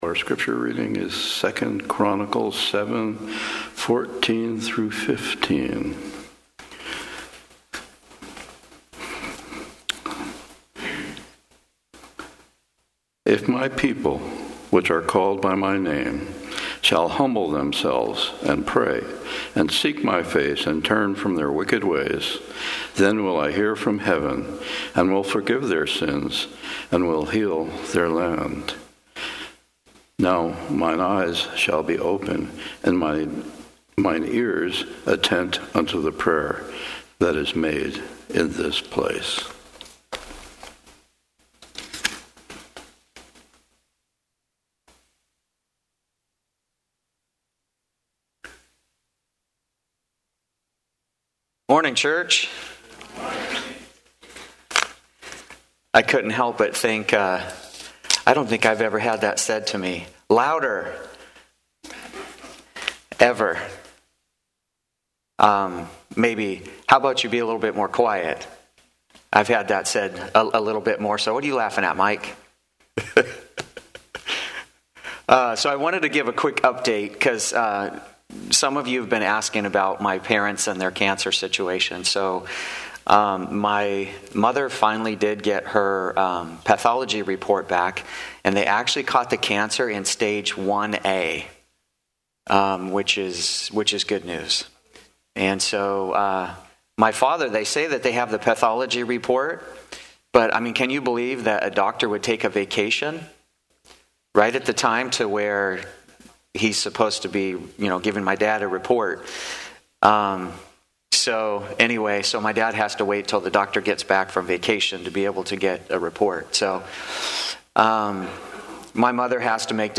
Seventh-day Adventist Church, Sutherlin Oregon